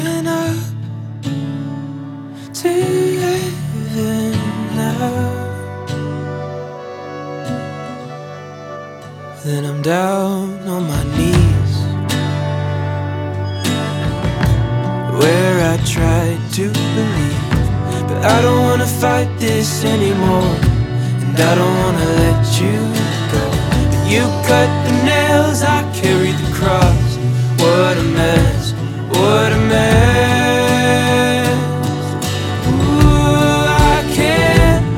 Alternative Folk Singer Songwriter
Жанр: Альтернатива / Фолк